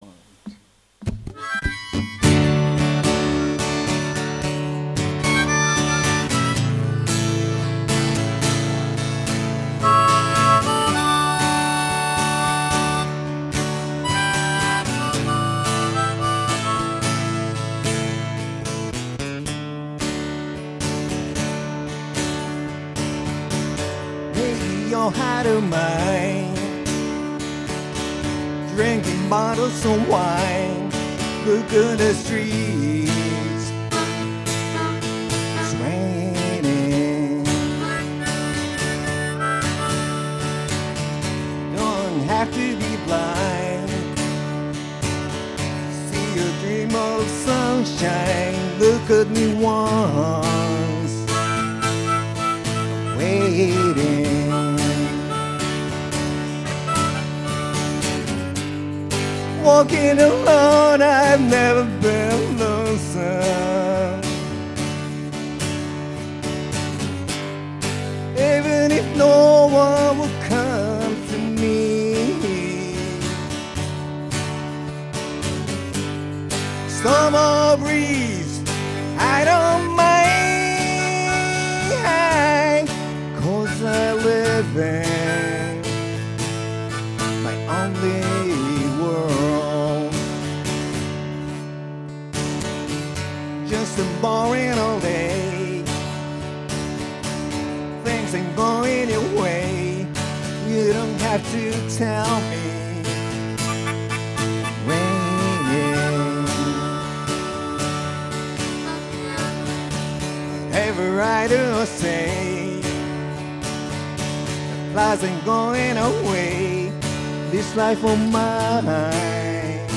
ライヴ